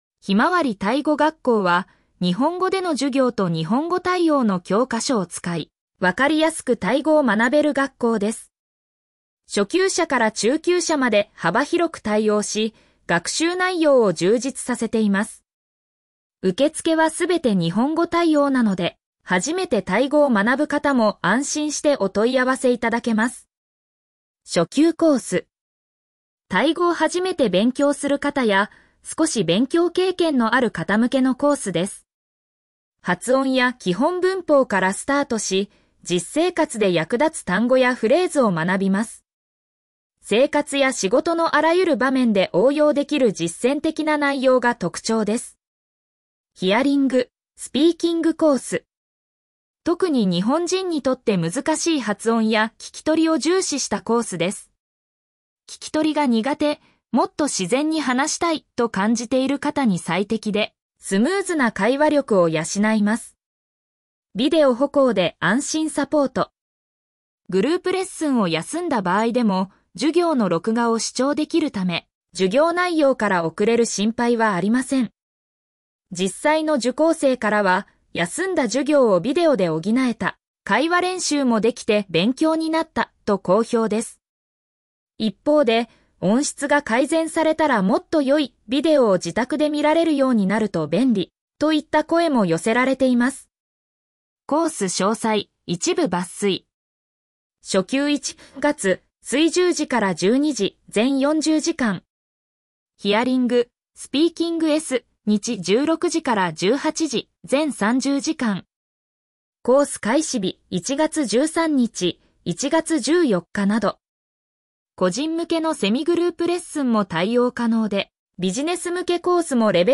ペルプ バンコク・メディプレックスビルディング リンク先 「ひまわりタイ語学校」授業風景 グループ 「ひまわりタイ語学校」授業風景 個人 読み上げ ひまわりタイ語学校は、日本語での授業と日本語対応の教科書を使い、わかりやすくタイ語を学べる学校です。